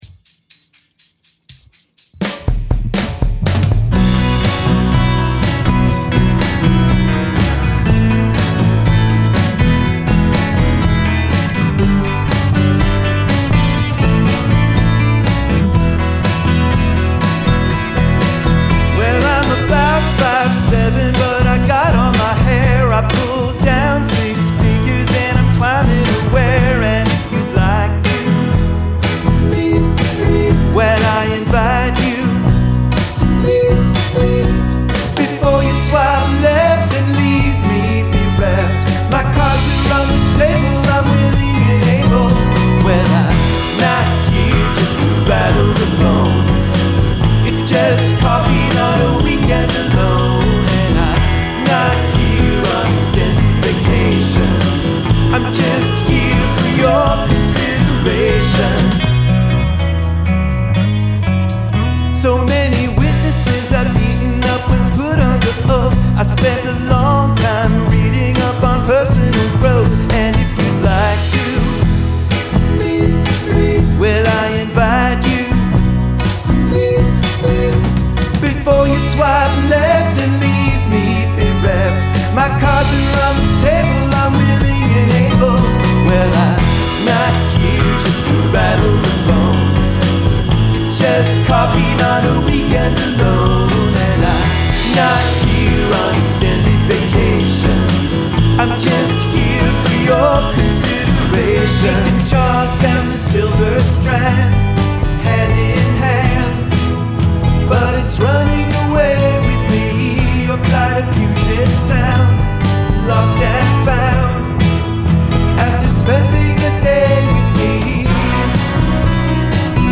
Acoustic 12-string
acoustic drums vs. electric drums